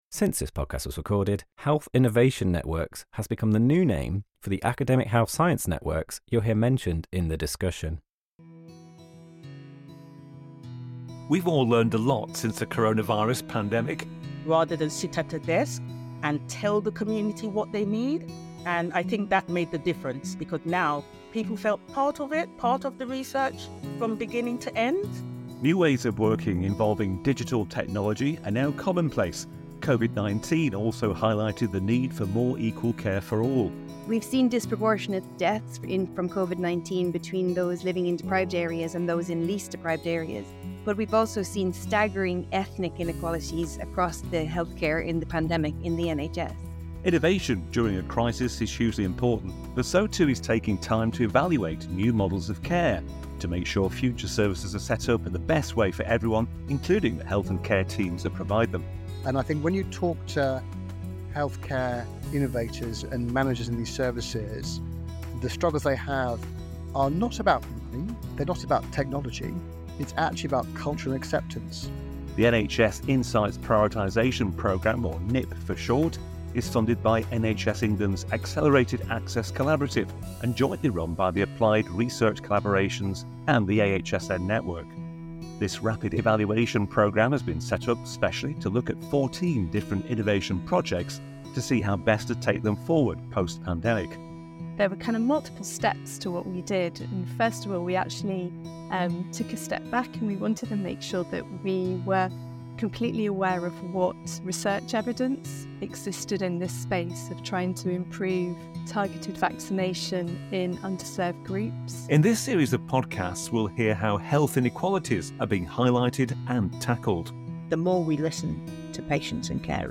In this edition we discuss how digital monitoring is making a difference and hear the experiences of three guests.